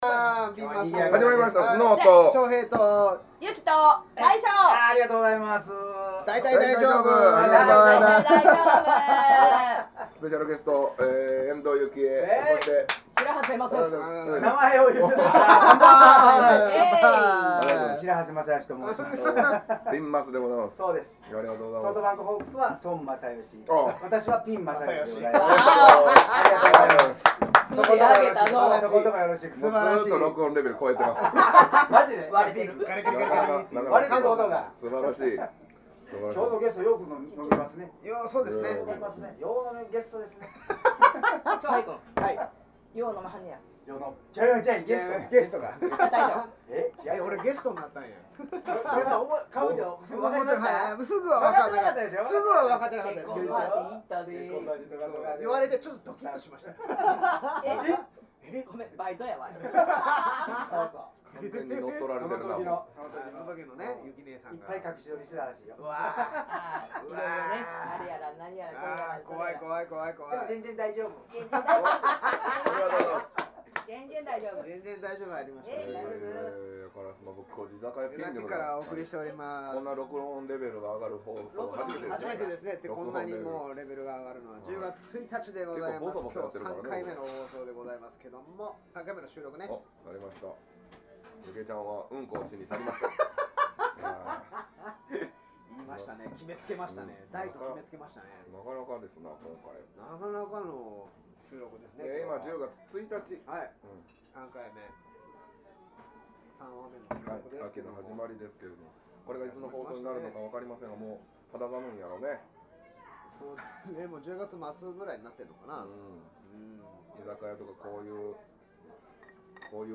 音が消えますが７：００あたりで戻ってきますので、心配せず聞き続けてください！